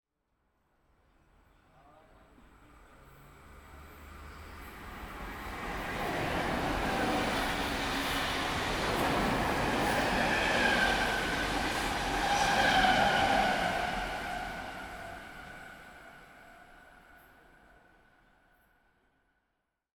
train_pass.ogg